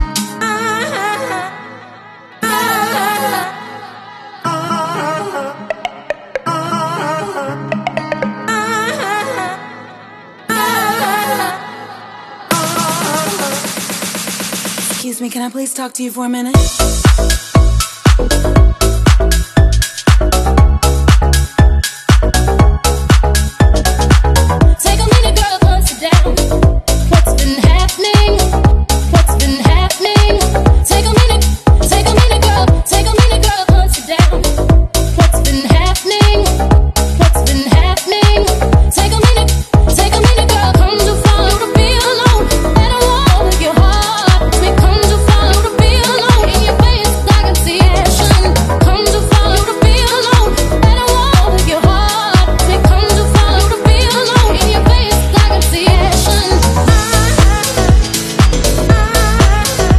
Go-Ahead ireland 11556 Volvo B5TL Wright eclipse Gemini 3 is seen @ Woodbrook college on a 45a to Dún Laoghaire